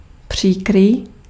Ääntäminen
Ääntäminen US : IPA : [ˈstip]